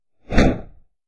FX " 降落伞
描述：伞的打开的立体声假音。也可以用作帆的升起。
标签： 拍打 变幅 变幅 降落伞打开 降落伞 XY 音响 弗利
声道立体声